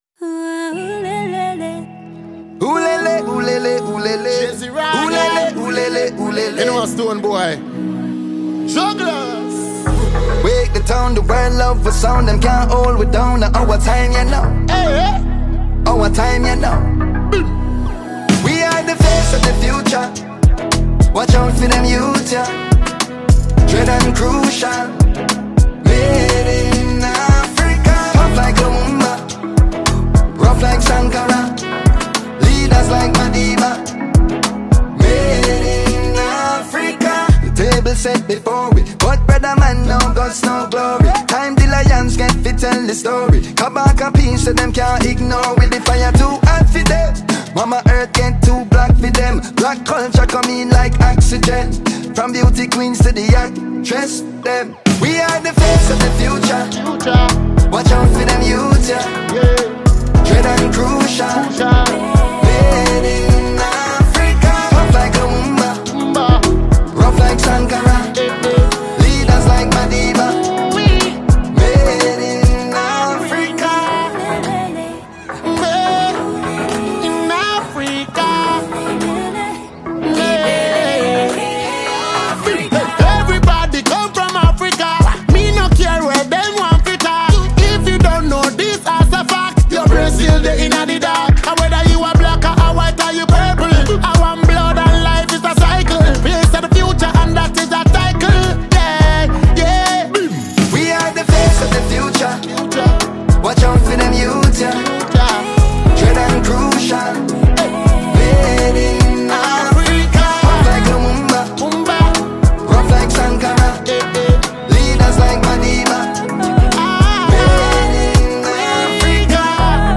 A well-known music artist and dancehall icon
afrobeat singer from Ghana